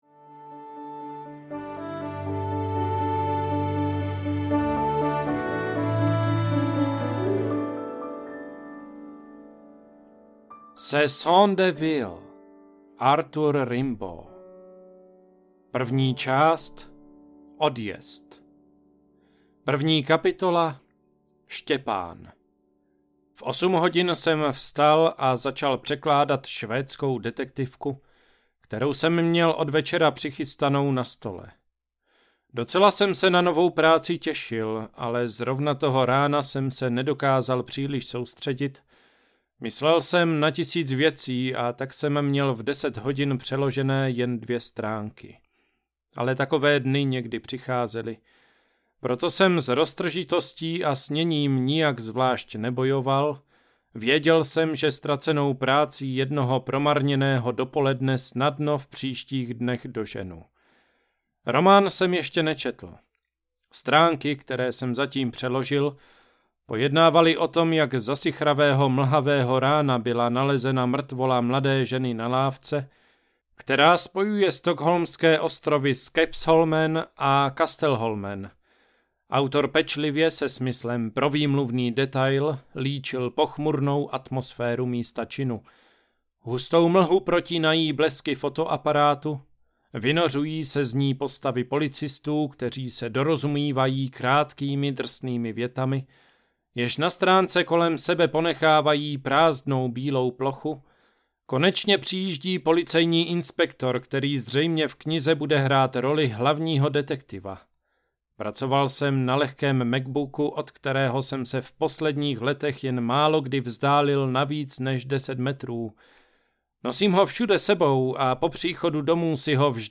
Města audiokniha
Ukázka z knihy